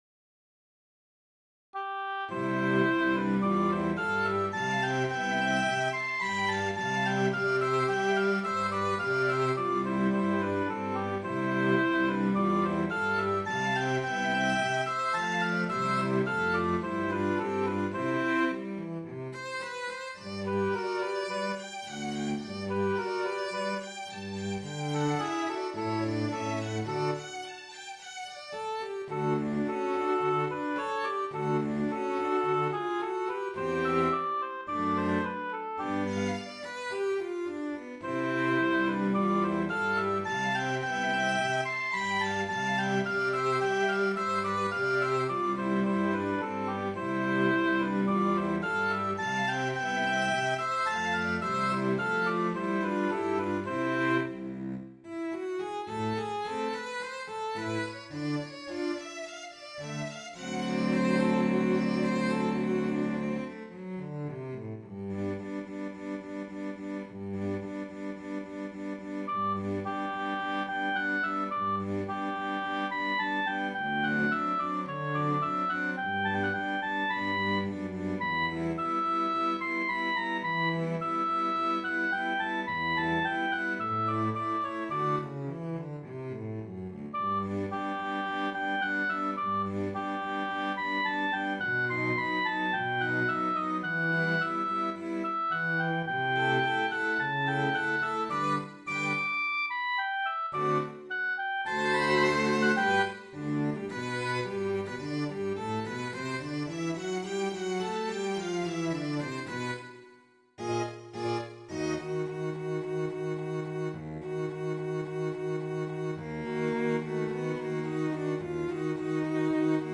The first movement of a Quartettino for oboe and strings, attributed to Swabia’s
by the renowned MacFinale Ensemble playing period midi instruments!